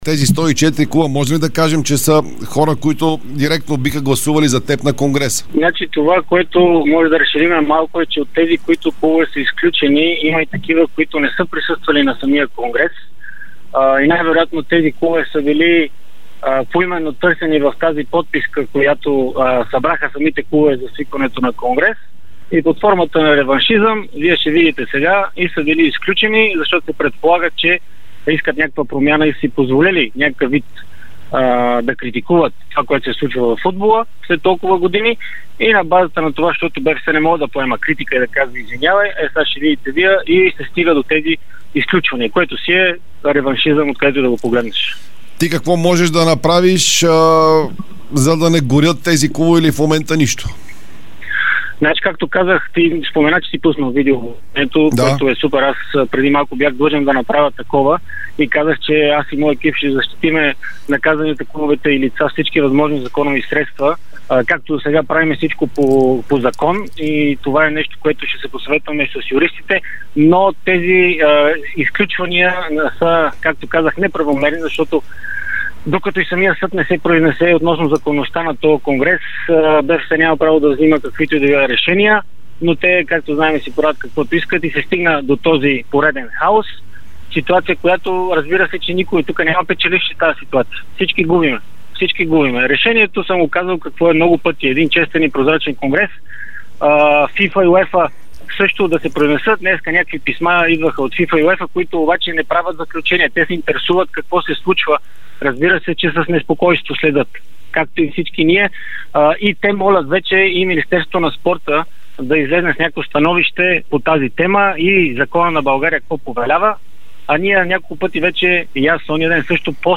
Димитър Бербатов даде специално интервю пред Дарик радио и dsport, в което коментира решенията на днешния Изпълком на БФС, който реши да изключи 104 от българските клубове от централата.